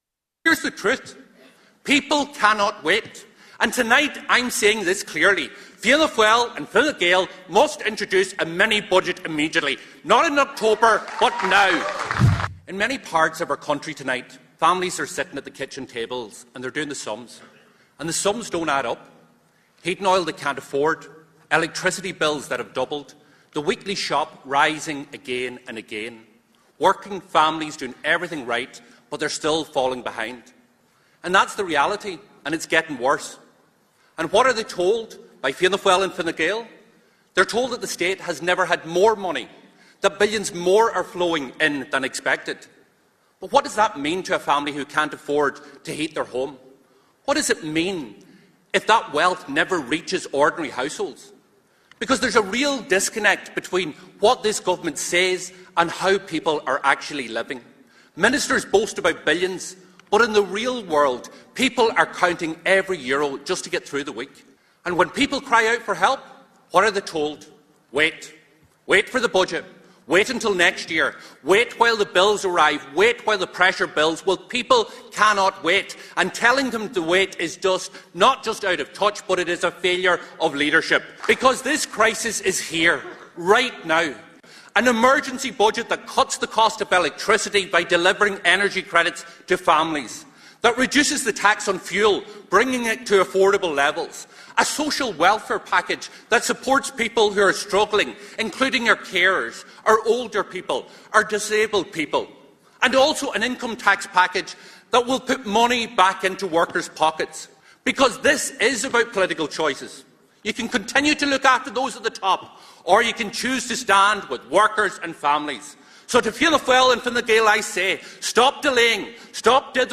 Donegal Deputy Pearse Doherty raised the issue while addressing Sinn Féin’s Ard Fheis in Belfast, saying many families are struggling to meet rising costs.